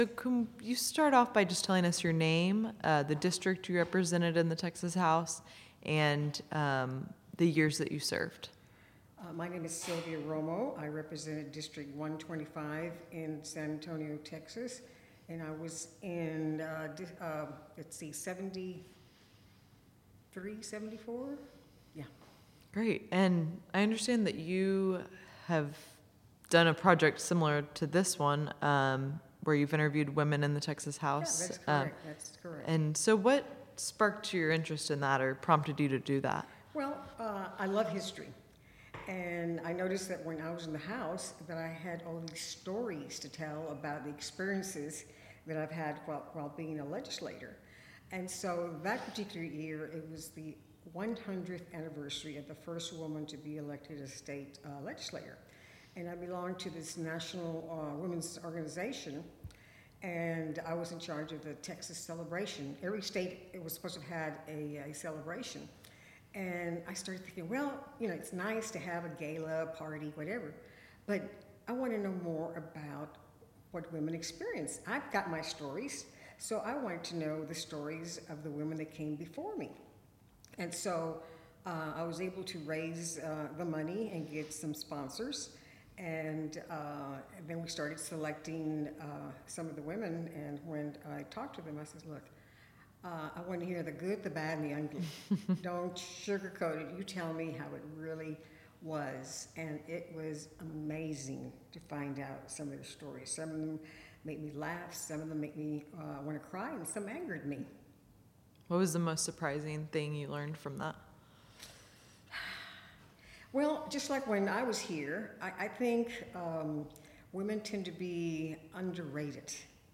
Oral history interview with Sylvia Romo, 2017. Texas House of Representatives .